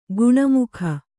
♪ guṇamukha